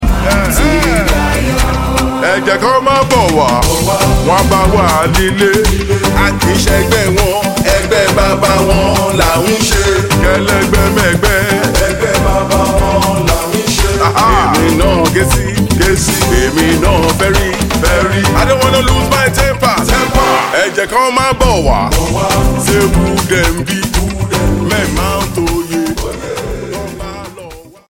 Backing vocals